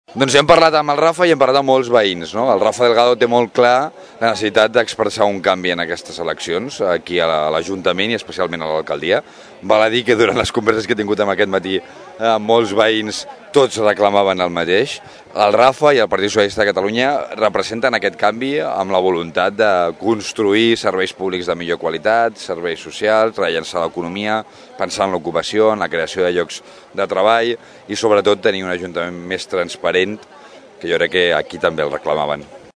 En declaracions als serveis informatius, López aposta per un canvi a Tordera el proper 24 de Maig.